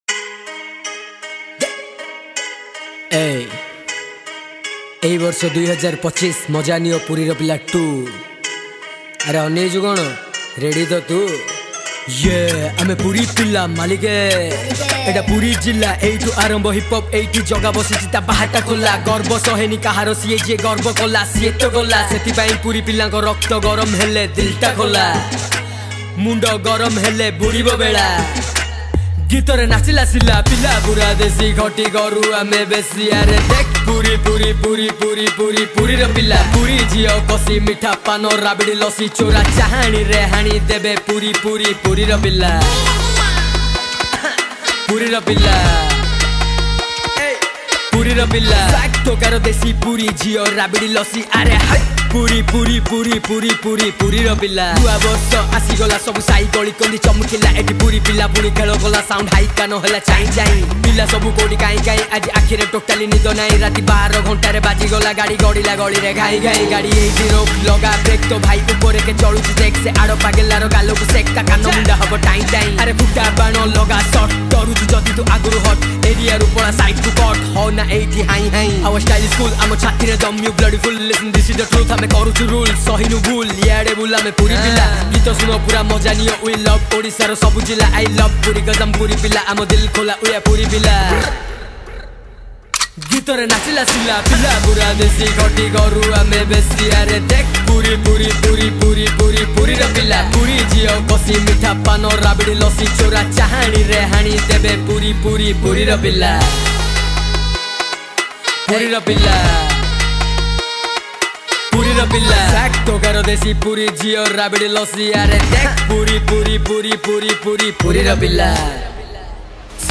Category : Odia Rap Song